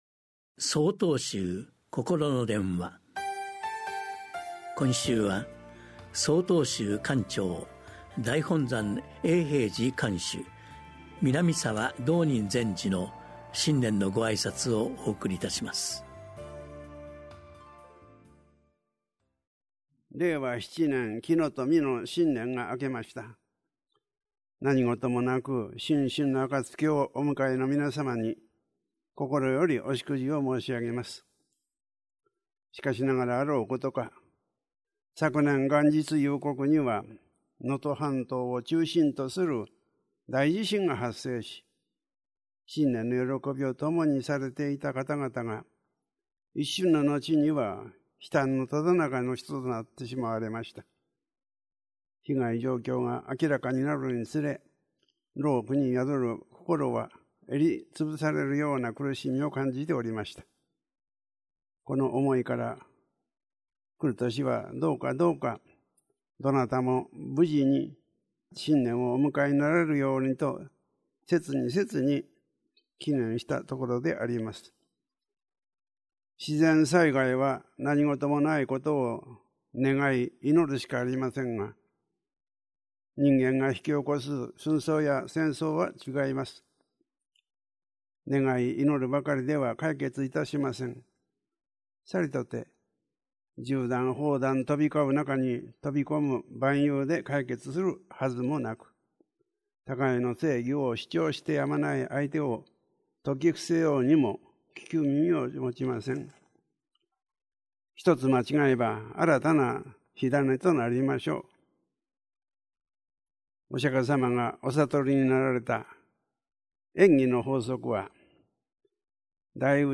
曹洞宗がお届けするポッドキャスト配信法話。 禅の教えを踏まえた「ほとけの心」に関するお話です。